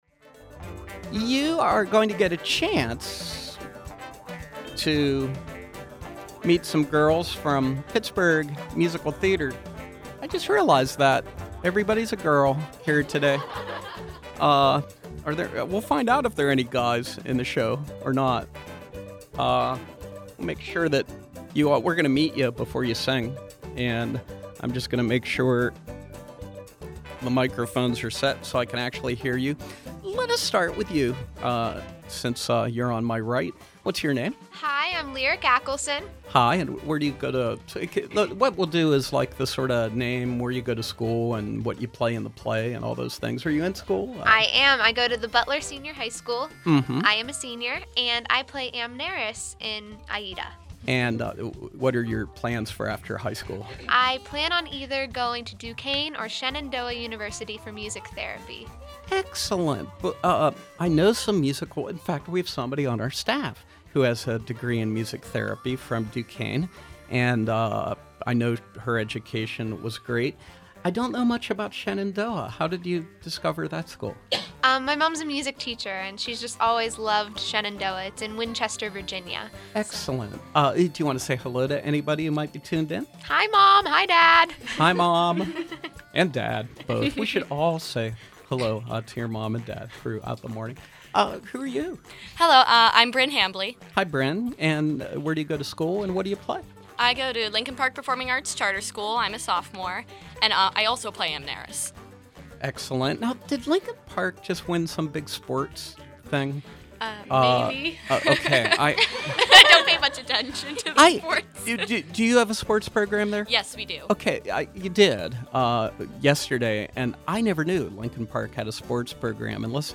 Members from the Pittsburgh Musical Theater Performing selections from their upcoming performance of “AIDA” the classic story of Giuseppe Verdi’s Italian opera with the contemporary music and style of Elton John and Tim Rice, live on SLB.